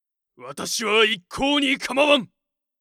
パロディ系ボイス素材　2